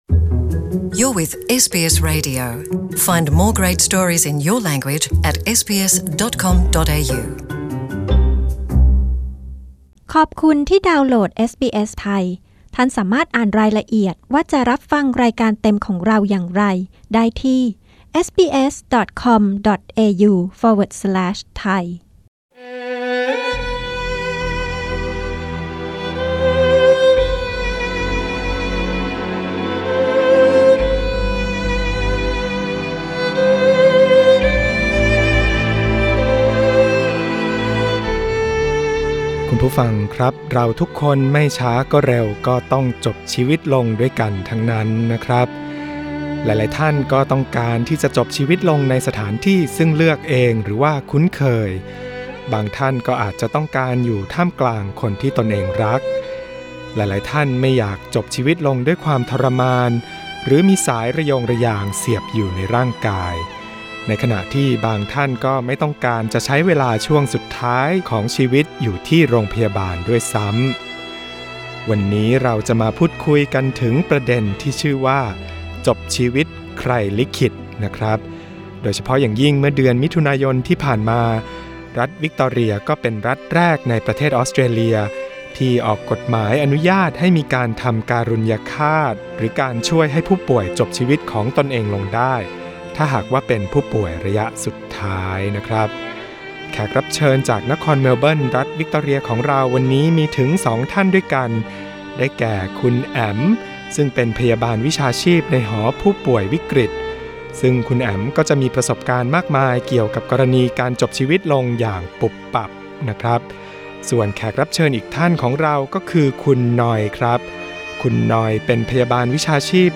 กดปุ่ม (▶) เพื่อฟังสัมภาษณ์พยาบาลผู้เชี่ยวชาญชาวไทยจากนครเมลเบิร์น Source: Image: Pixabay